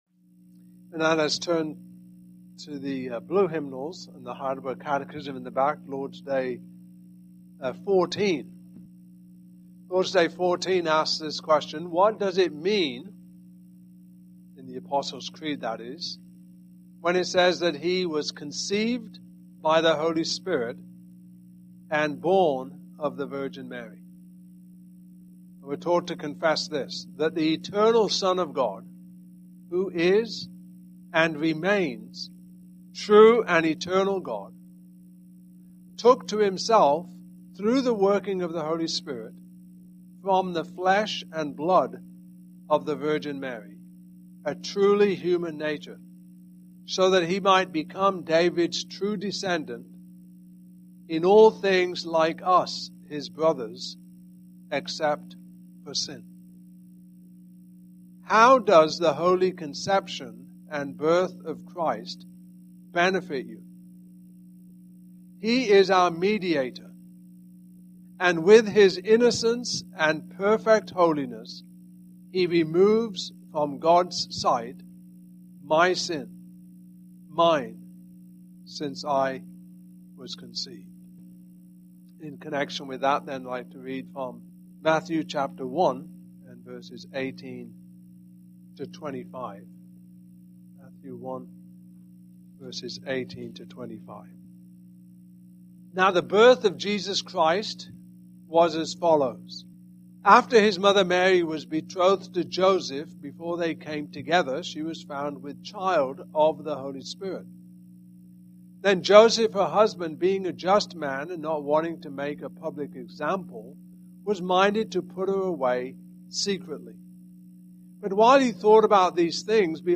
Passage: Matthew 1:18-25 Service Type: Evening Service